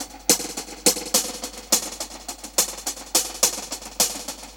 Index of /musicradar/dub-drums-samples/105bpm
Db_DrumsB_EchoHats_105-01.wav